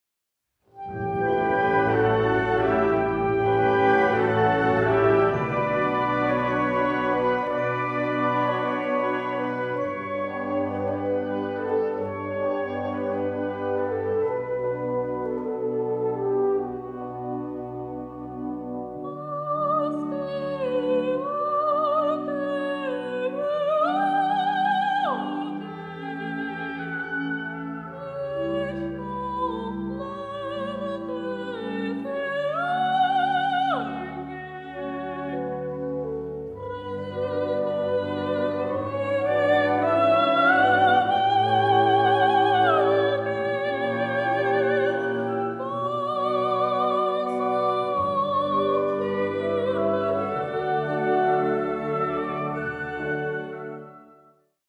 独唱版（高声用）
独唱の音域はG4-G5